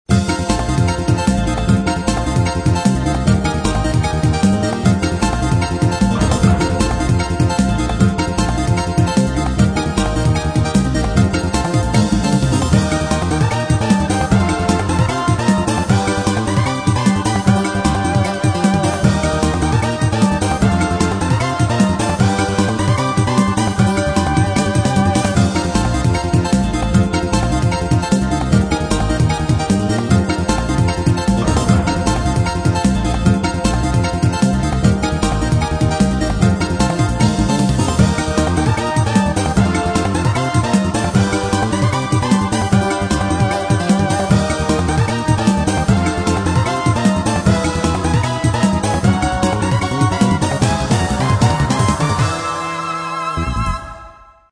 コンガもちょっと強すぎだったし。